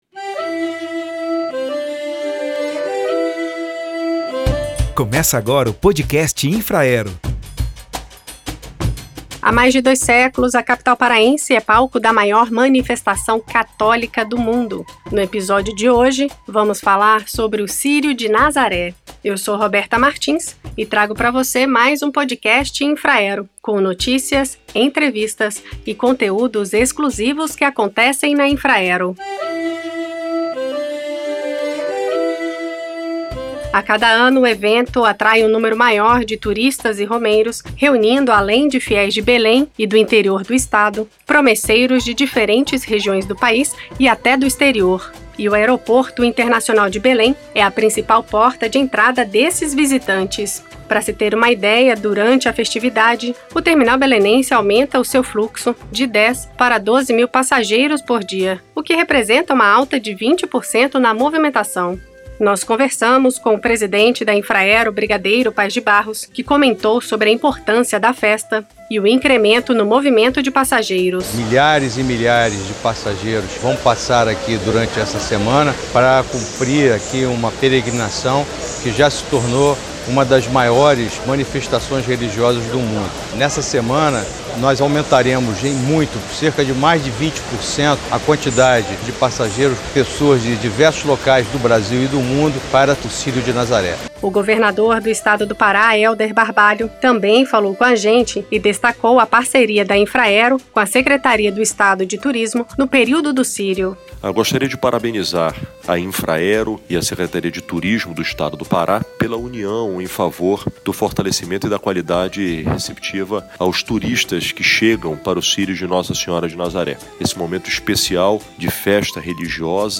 Neste episódio trazemos uma entrevista com o presidente da Infraero, Brigadeiro Paes de Barros, com o governador do Pará, Helder Barbalho, e turistas que desembarcaram em Val-de-Cans para o Círio.